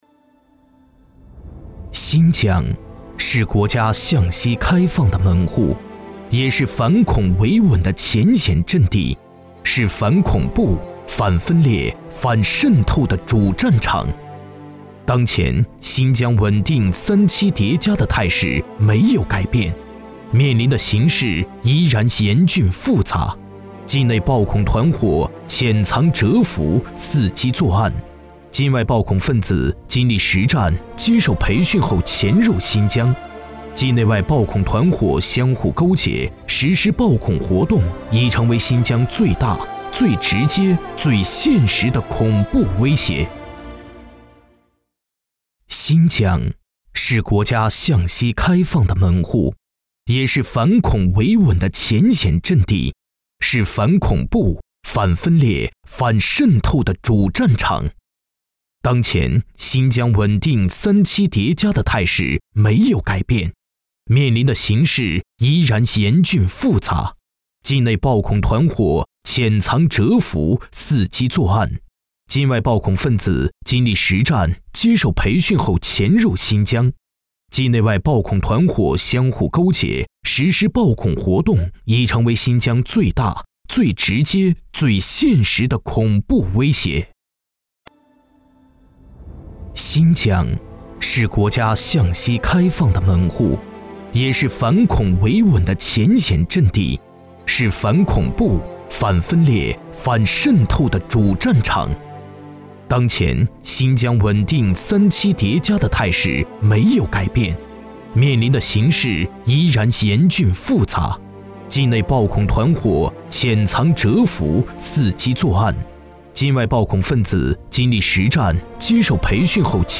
• 男B073 国语 男声 专题纪录-反恐利刃 大气浑厚磁性|沉稳